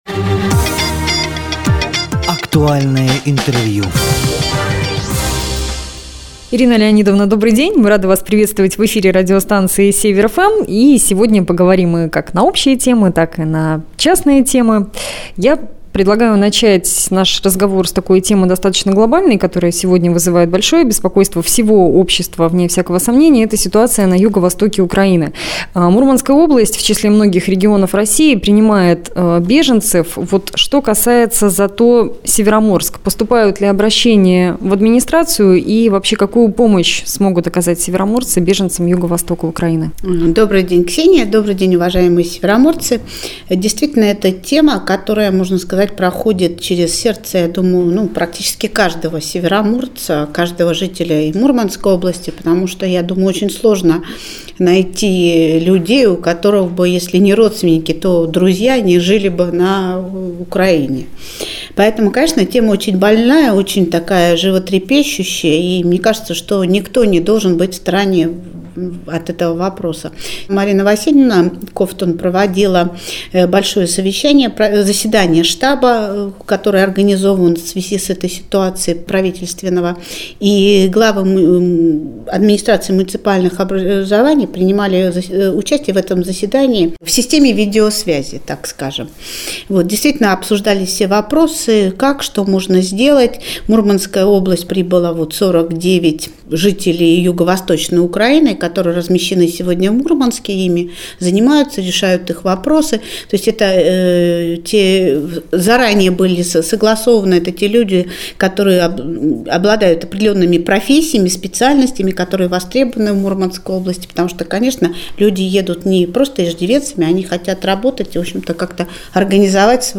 Глава администрации ЗАТО г.Североморск Ирина Норина приняла участие в прямом эфире радио «Север-FM». Темы актуального интервью - вопросы, связанные с беженцами из Украины, а также насущные проблемы благоустройства города, и в частности, организация муниципальных стоянок, ремонт дорог, а также - результаты подготовки к Дню ВМФ.